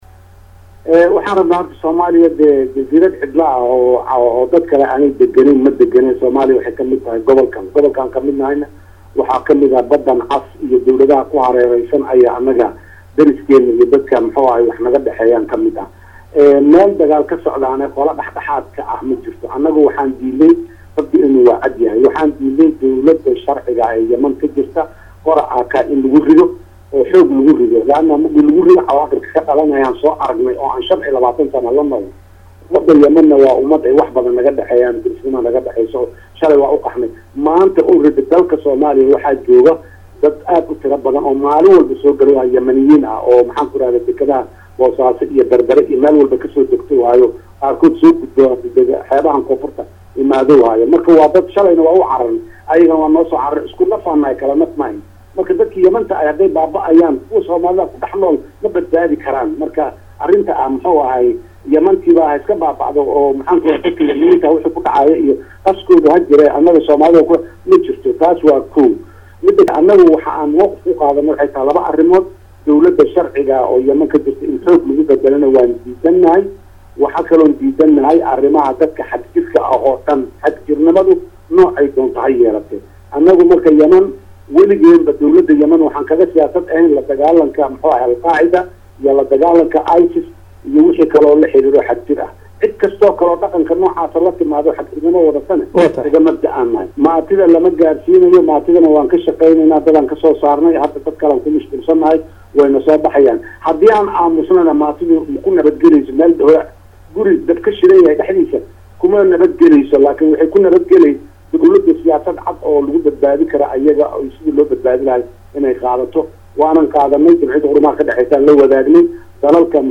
Madaxweynaha oo waraysi siinayey Idaacadda VOA-da Laanteed afka Soomaaliya ayaa waxaa la weydiiyey in siagu uu qaadayo masuuliyadda dhibaatada la gaarsiiyo dadka Soomaaliyeed ee dalka YAman maadaama uu qaatay go’aan lagu deg degay oo keeni kara in Soomaalida la bartilmaameedsado.